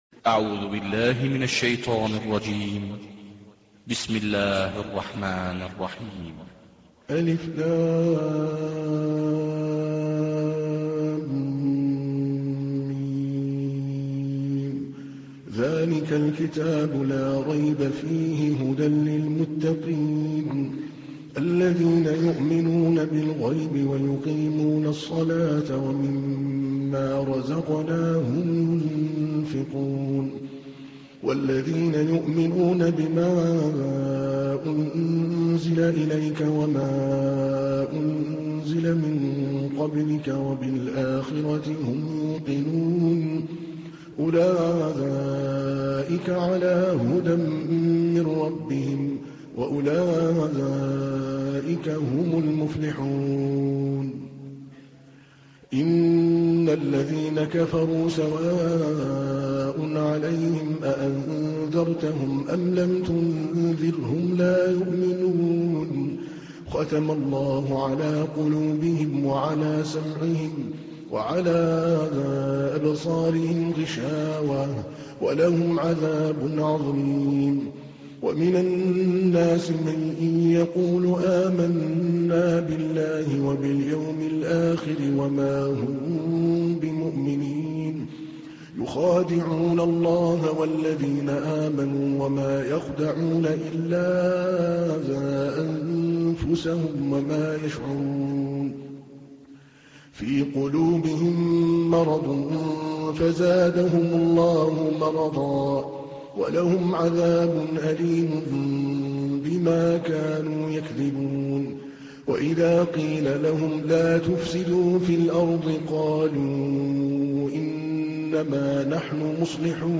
تحميل : 2. سورة البقرة / القارئ عادل الكلباني / القرآن الكريم / موقع يا حسين